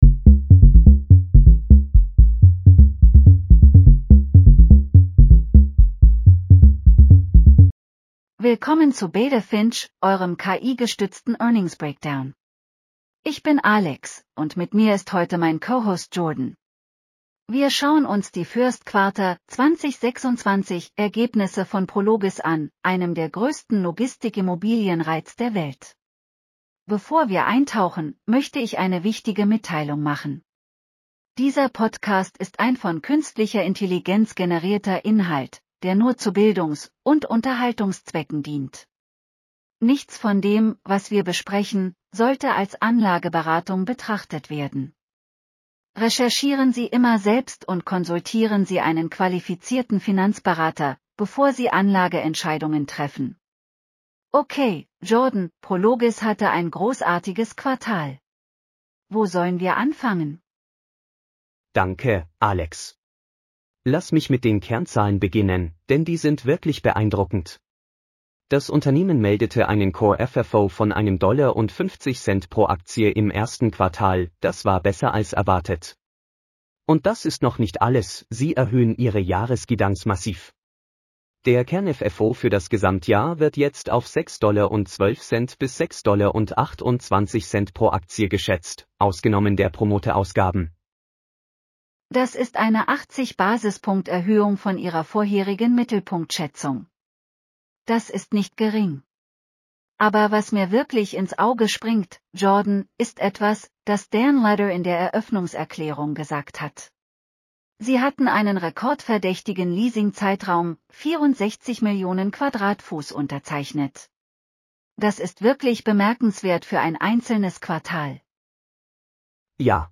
Prologis Q1 2026 earnings call breakdown. Full transcript & podcast. 9 min. 5 languages.